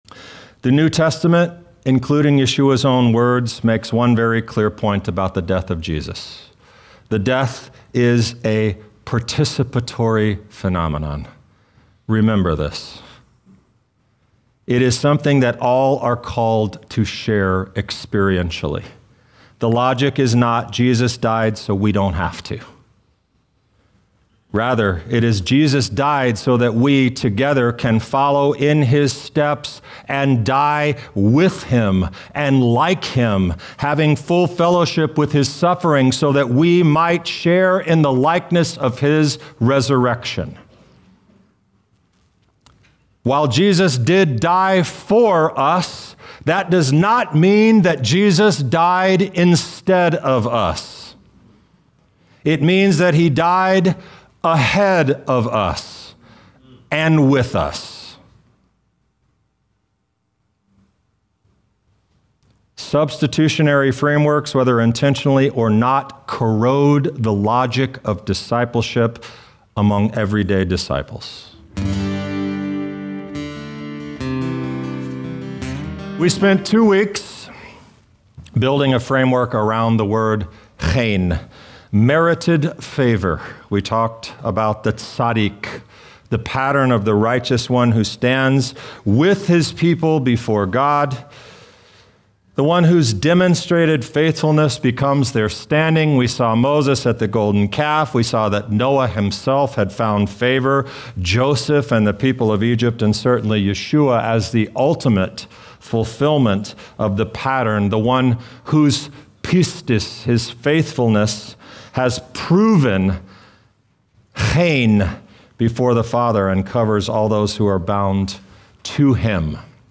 This teaching explores the biblical text carefully and argues that the Passover story may not mean what many of us were taught to assume.